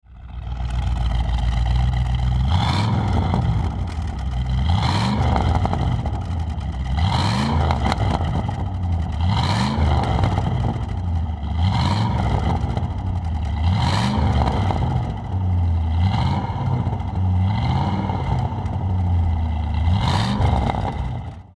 Klingeltöne
Thunderbird_sound1.mp3